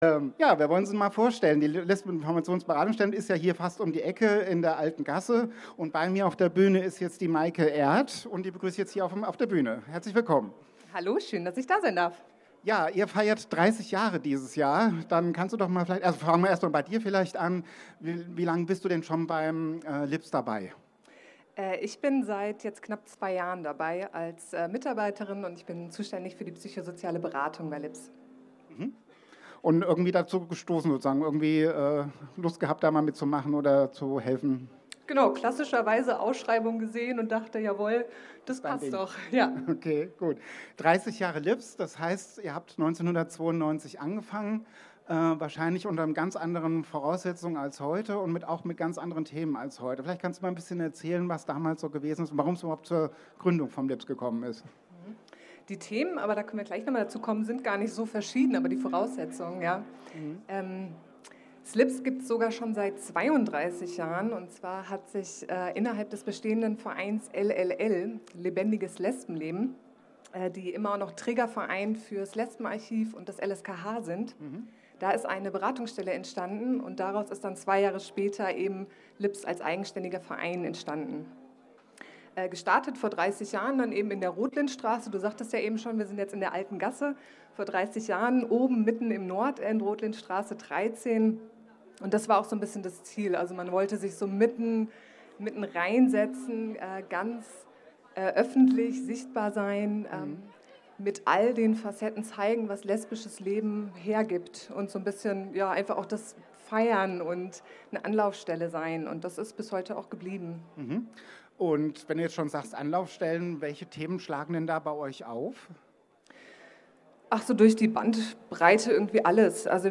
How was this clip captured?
Mitschnitt einer Diskussion auf der Kulturbühne vom CSD 2022